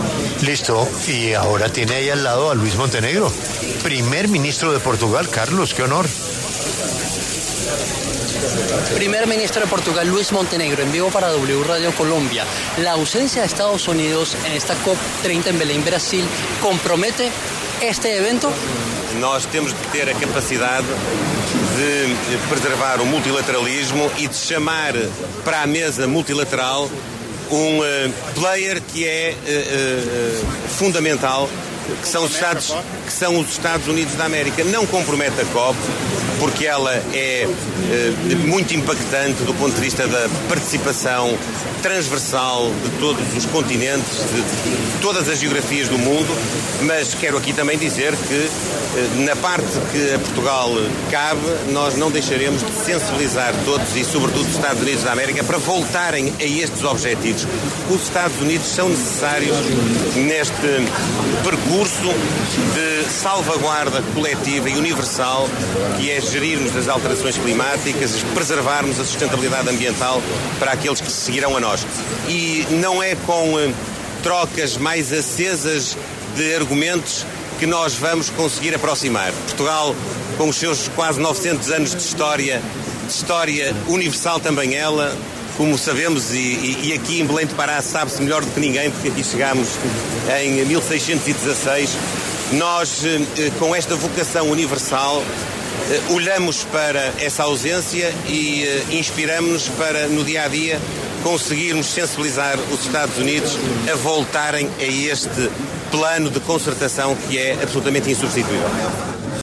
En el marco de la COP30, que se lleva en Belém, Brasil, habló, para los micrófonos de La W, el primer ministro de Portugal, Luis Montenegro, y se refirió a la ausencia de Estados Unidos en este evento.